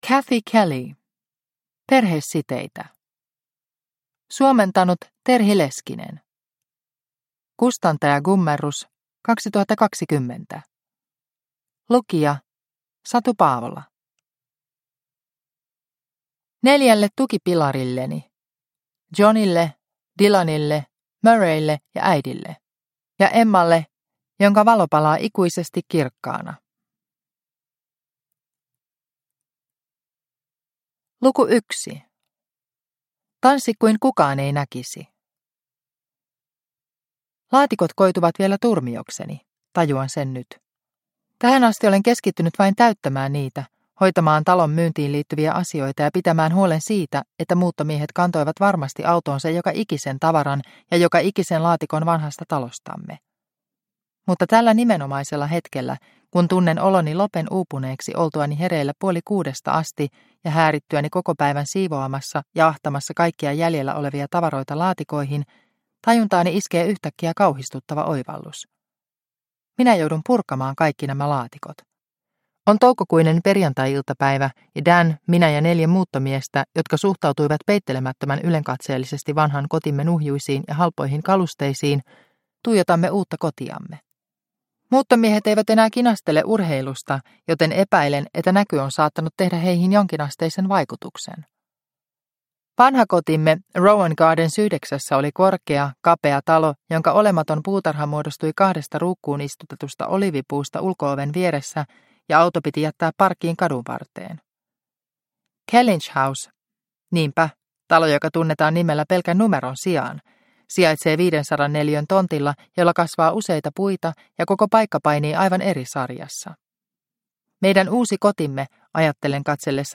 Perhesiteitä – Ljudbok – Laddas ner